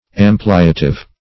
Ampliative \Am"pli*a*tive\, a. (Logic)